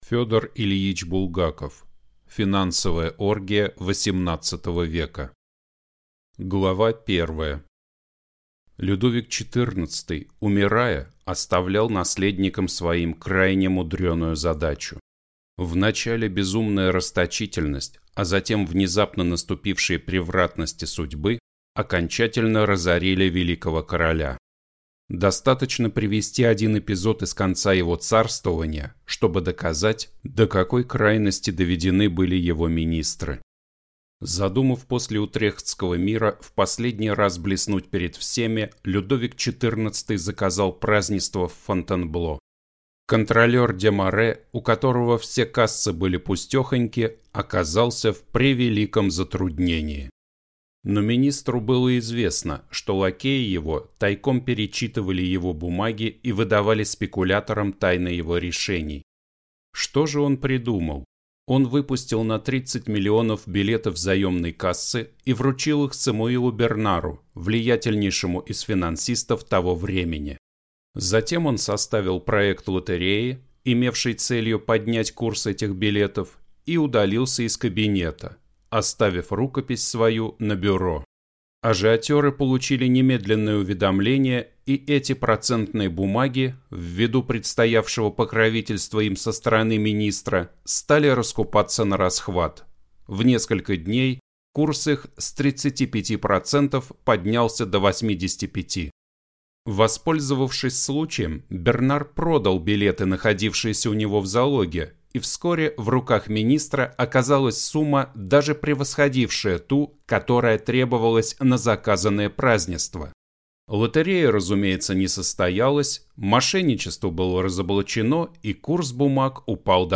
Аудиокнига Финансовая оргия XVIII века | Библиотека аудиокниг